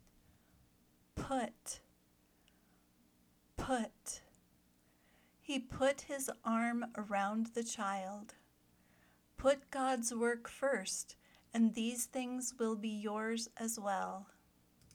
/pʊt/  (verb)